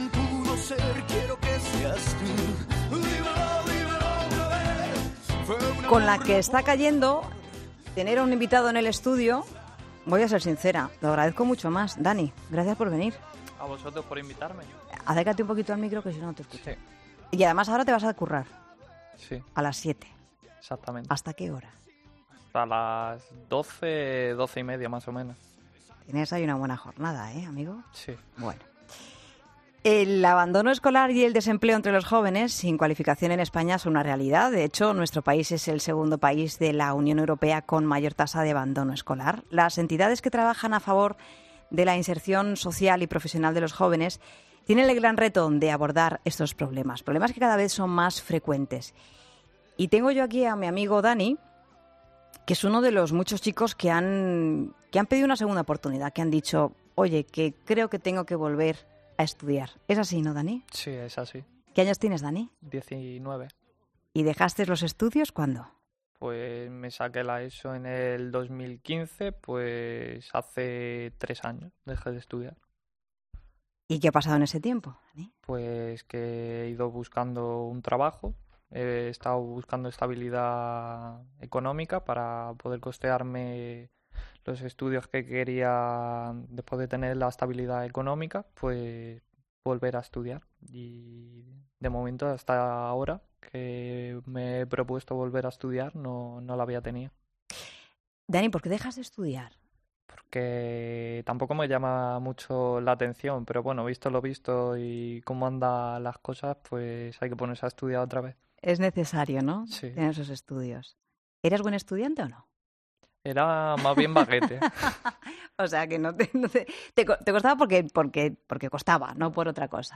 Hablamos en 'La Tarde'